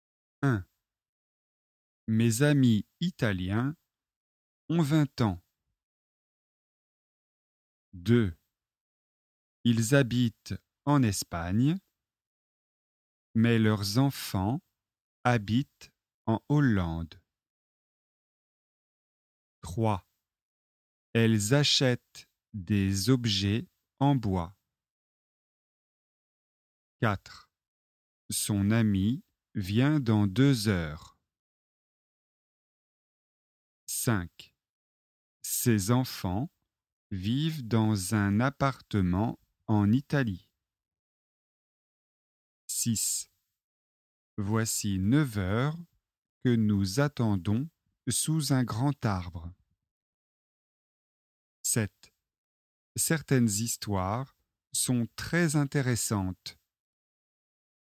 Leçon de phonétique et de prononciation sur les liaisons obligatoires
EXERCICE : Lisez les phrases suivantes à haute voix.